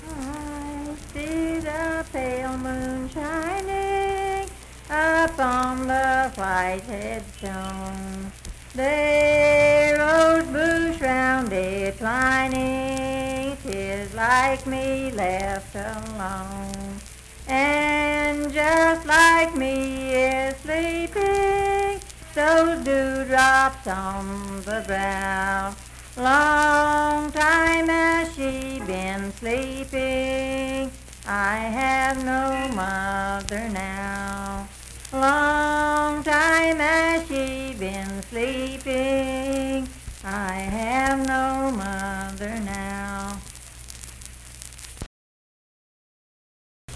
Unaccompanied vocal music
Verse-refrain 3(2-4).
Voice (sung)